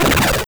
Fireball2.wav